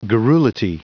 Prononciation du mot garrulity en anglais (fichier audio)
Prononciation du mot : garrulity